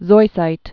(zoisīt)